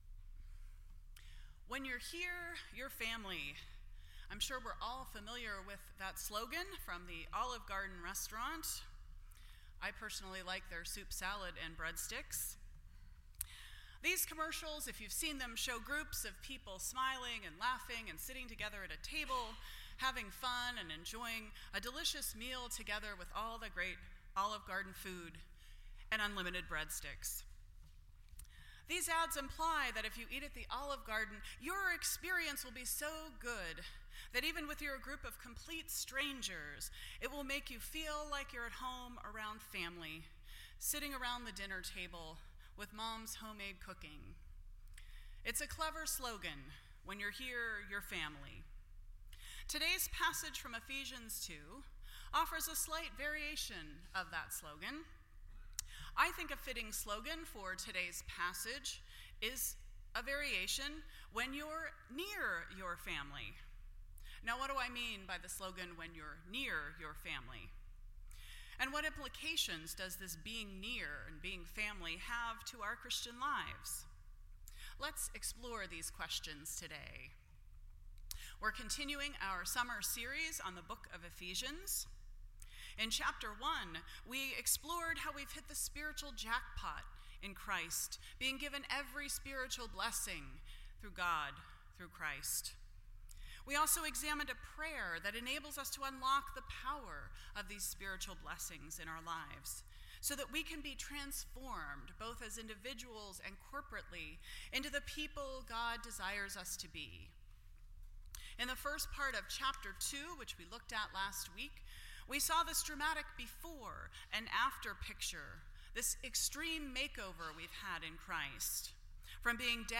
Ephesians Service Type: Sunday Morning %todo_render% Share This Story